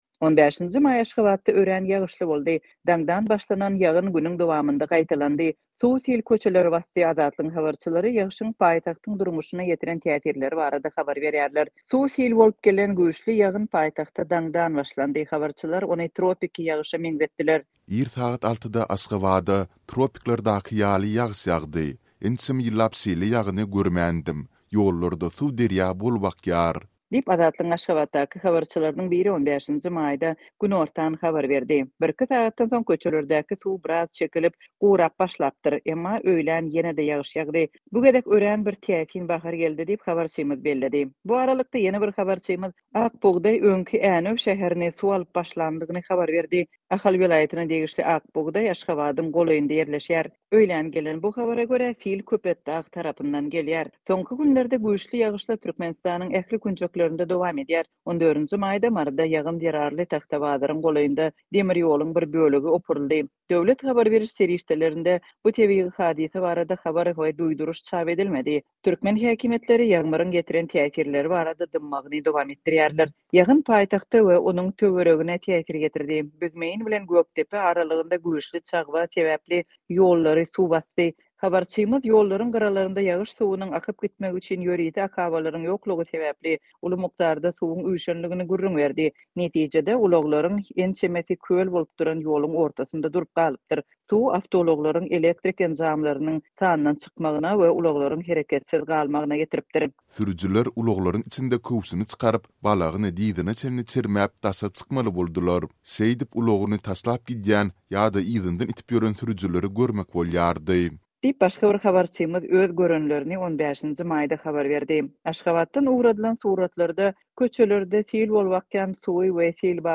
Suw-sil köçeleri basdy. Azatlygyň habarçylary ýagyşyň paýtagtyň durmuşyna ýetiren täsirleri barada habar berýärler.